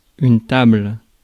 Ääntäminen
France: IPA: [yn tabl]